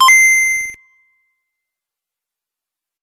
8-bit-coin-fx_G_minor.wav